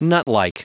Prononciation du mot nutlike en anglais (fichier audio)